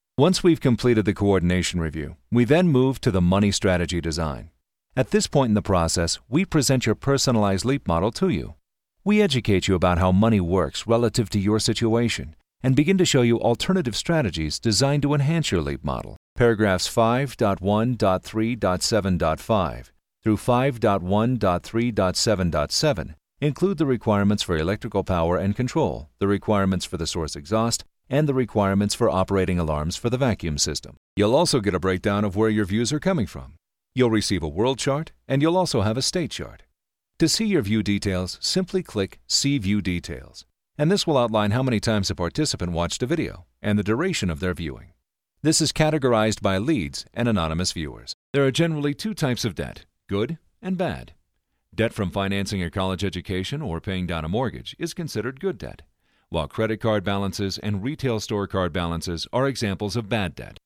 Male
Adult (30-50), Older Sound (50+)
E-Learning
E-Leaning Demo Explainer
Words that describe my voice are Authoritative, Storyteller, Warm.
All our voice actors have professional broadcast quality recording studios.